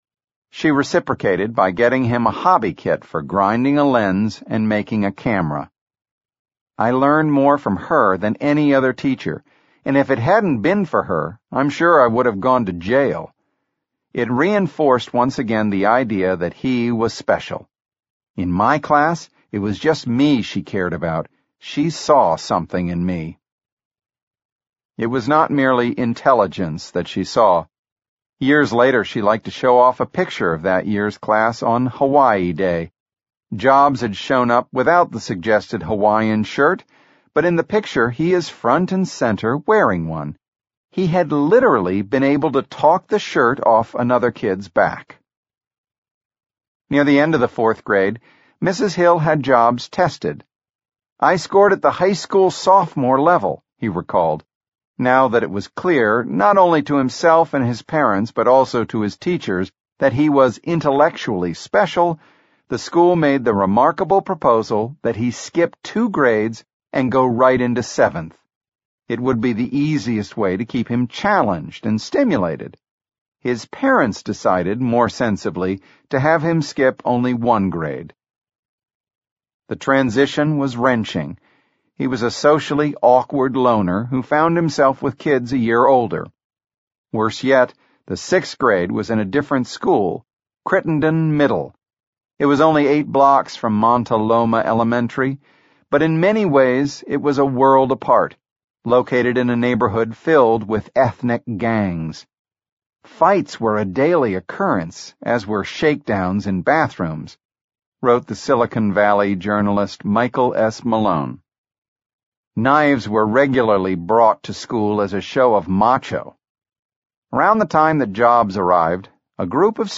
在线英语听力室乔布斯传 第14期:上学(2)的听力文件下载,《乔布斯传》双语有声读物栏目，通过英语音频MP3和中英双语字幕，来帮助英语学习者提高英语听说能力。
本栏目纯正的英语发音，以及完整的传记内容，详细描述了乔布斯的一生，是学习英语的必备材料。